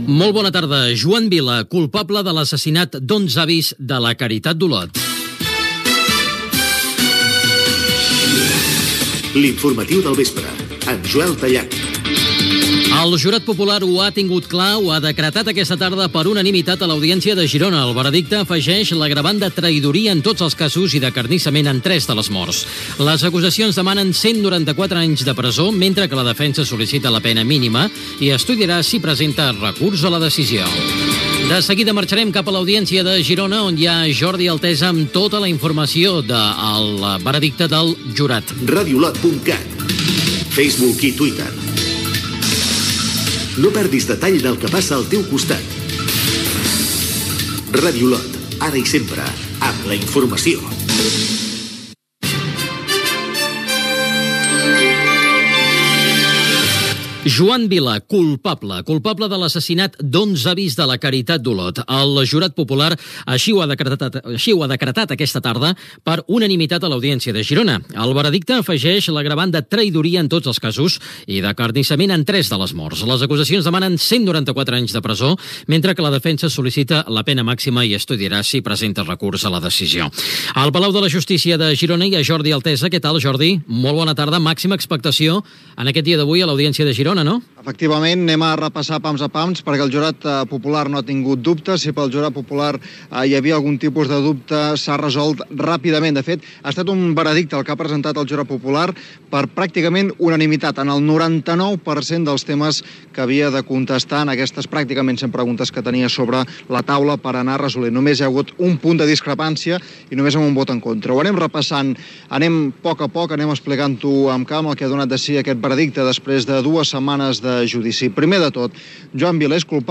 Titular, careta del programa, veredicte per la mort d'onze avis del geriàtric La Caritat, indicatiu del programa, connexió amb el Palau de la Justícia de Girona.
Informatiu